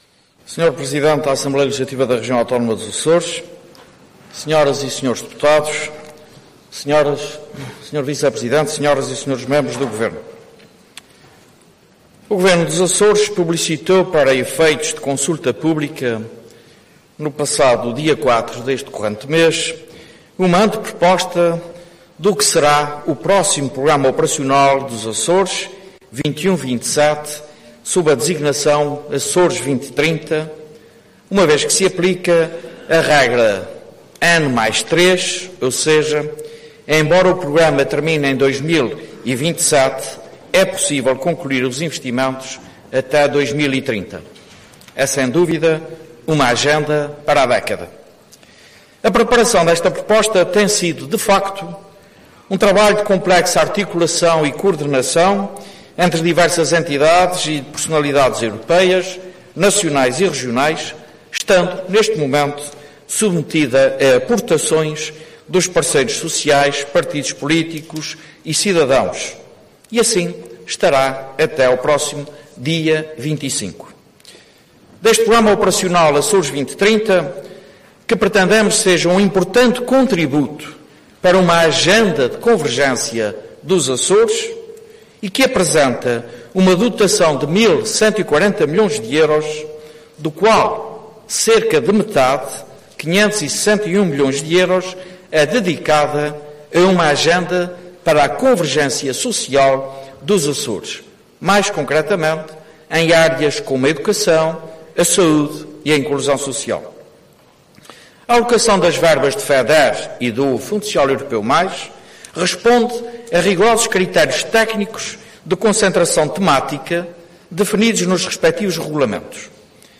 O Presidente do Governo Regional dos Açores, José Manuel Bolieiro, apresentou hoje à Assembleia Legislativa Regional uma comunicação a propósito do Programa Operacional Açores 2030, declarando que deve haver um “especial esforço” para a convergência social e económica em prol do desenvolvimento da Região.